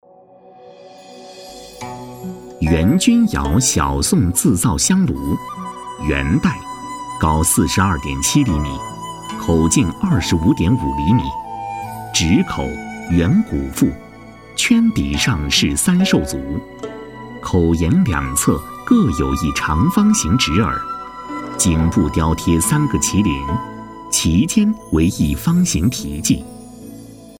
配音风格： 磁性，年轻
【专题】文物介绍